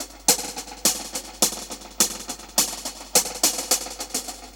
Index of /musicradar/dub-drums-samples/105bpm
Db_DrumsB_EchoHats_105-03.wav